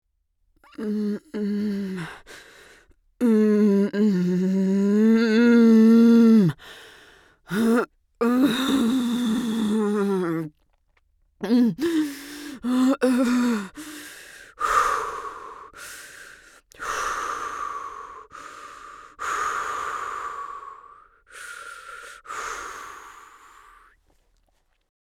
Woman Giving Birth Light Moan